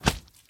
mob / magmacube / big2.ogg